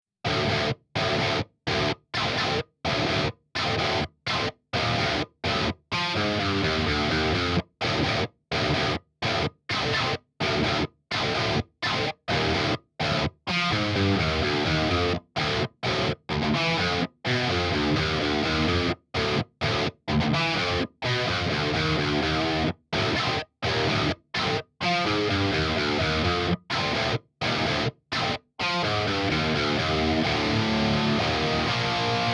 Фаза в гитарном дабл-треке.
Дабл-трек НЕ искуственный, гитары качественные, реамп Neural DSP.